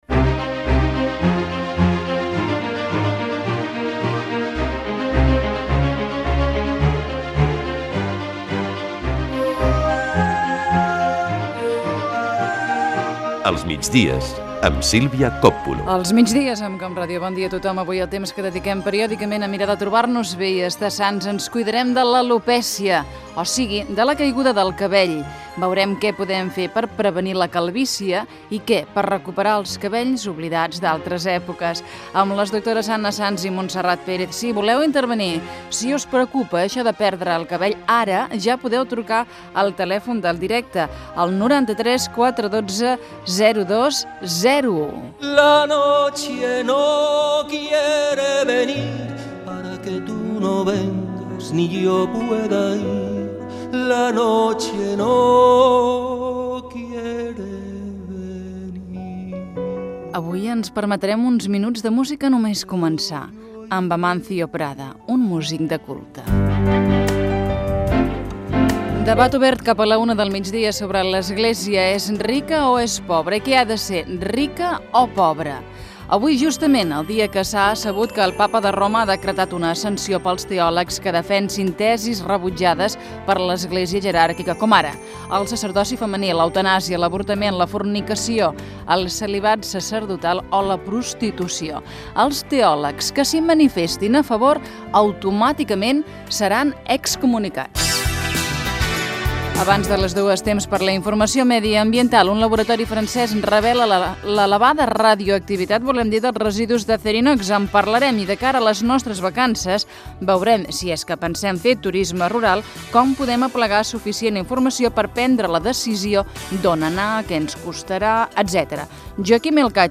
Inici i sumari del programa. Fragment d'una entrevista al cantautor Amancio Prada.